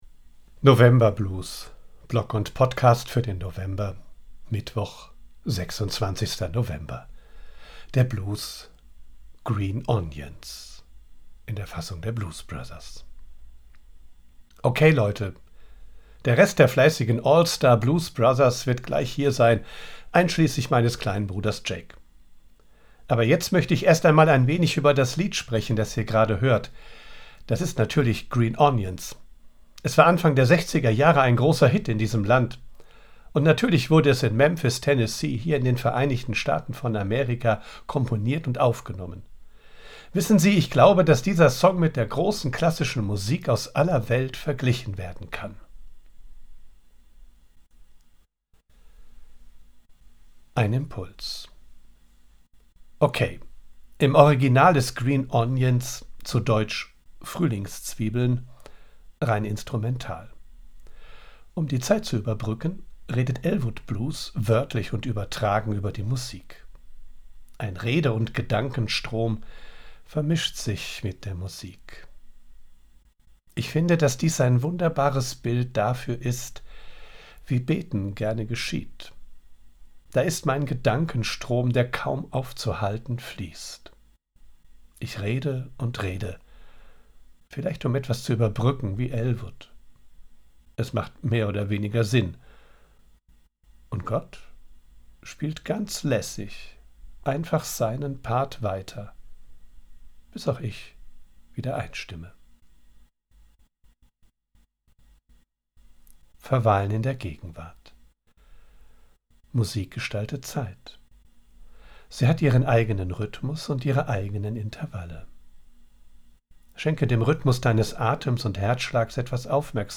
00:00:00 Der Blues: Green Onions
Die Fassung der Blues Brothers: